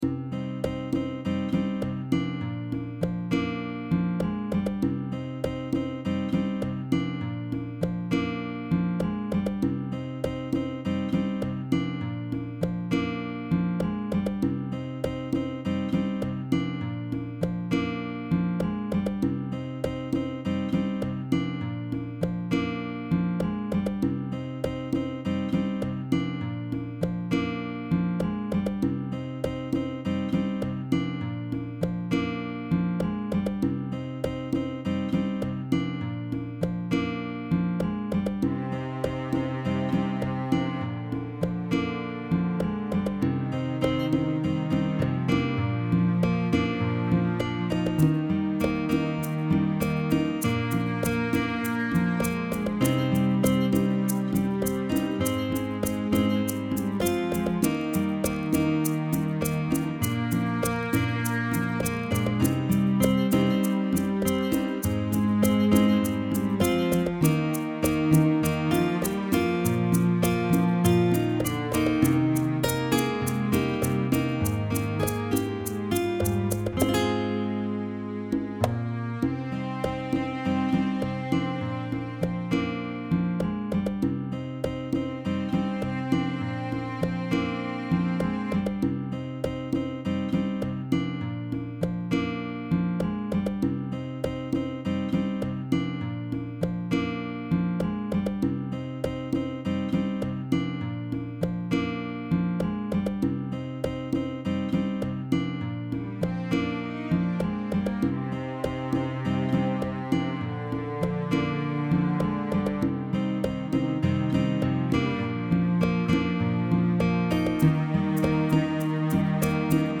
Voicing SATB Instrumental combo Genre Rock
Ballad